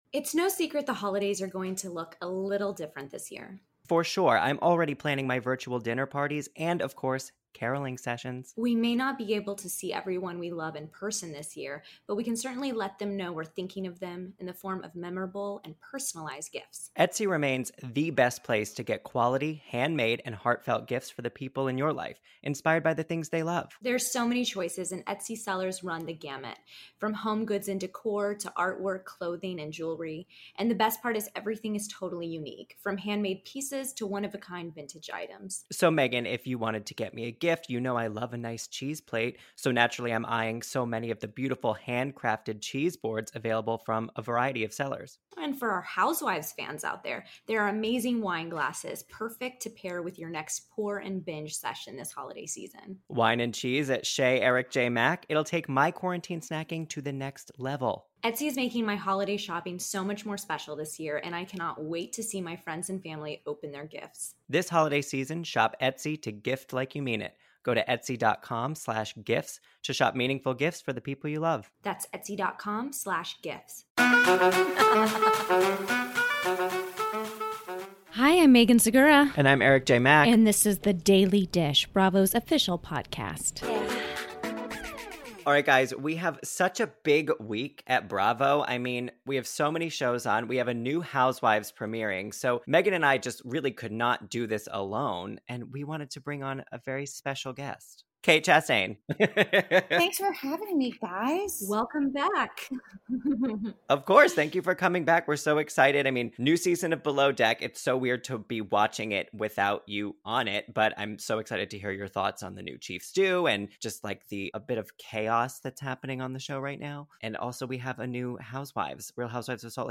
Plus, new Southern Charm cast member Leva Bonaparte calls in to discuss her first season, her friendship with Cameron Eubanks and how she’s essentially the Lisa Vanderpumo of South Carolina, and more.